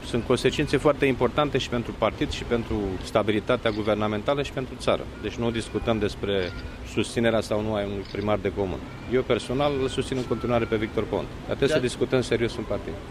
Iar situaţia premierului Victor Ponta va fi discutată în partid şi cu partenerii din coaliţie, a afirmat, astăzi, la Ploieşti, preşedintele interimar al social-democraţilor, Liviu Dragnea.